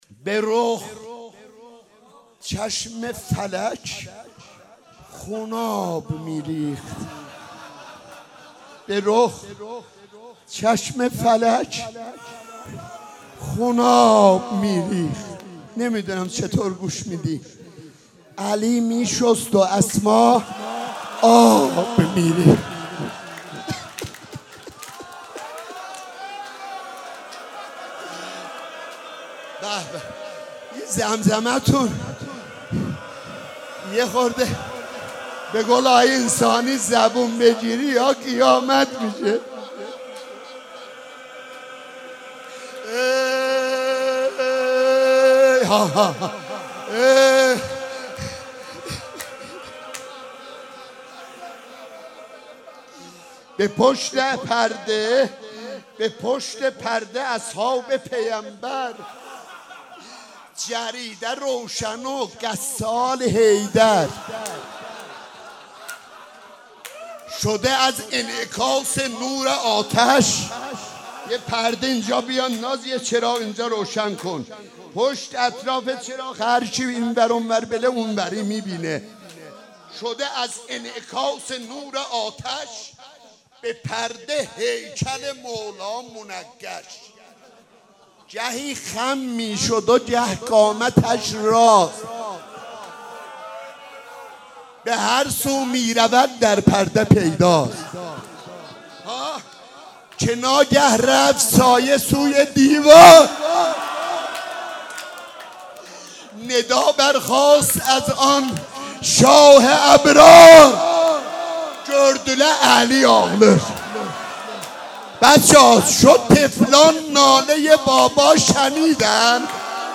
هیئت انصار العباس(ع)/مراسم هفتگی
روضه حضرت زهرا(س)